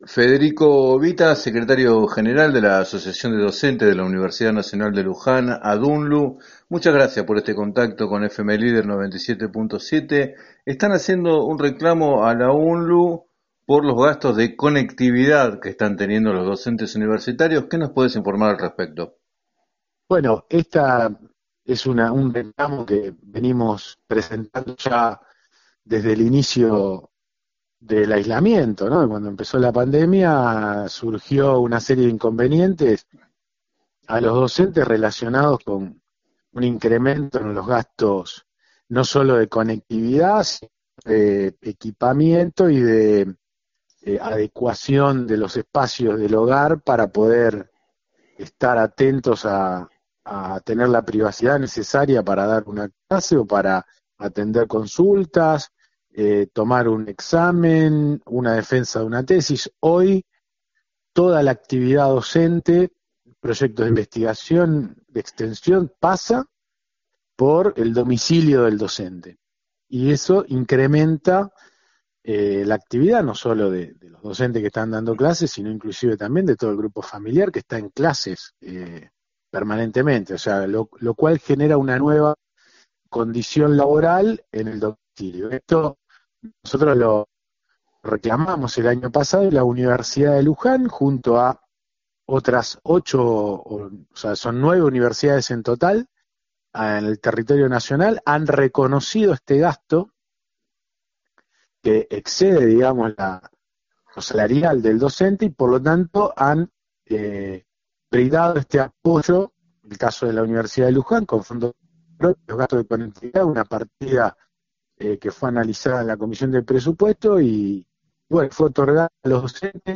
En declaraciones a FM Líder 97.7